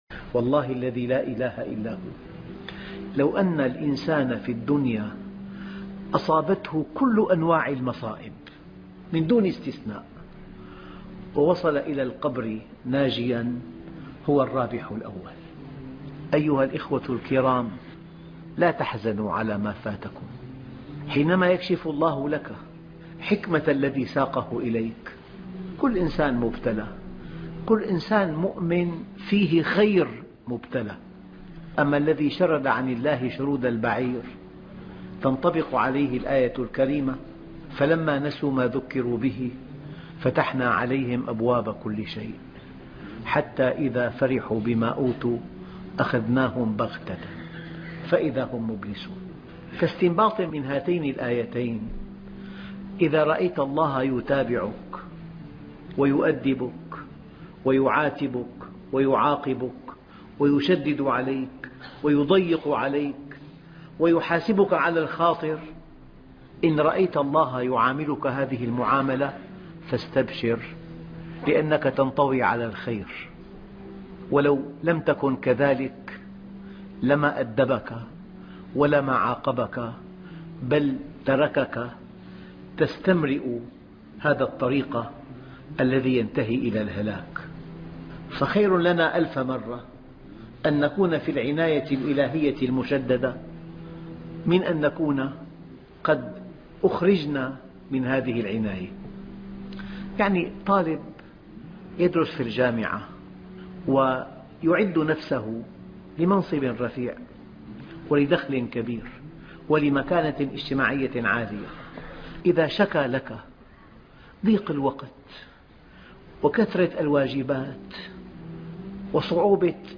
كيف يفتح الله لك أبواب الرزق من حيث لا تحتسب موعظة مؤثرة - الشيخ محمد راتب النابلسي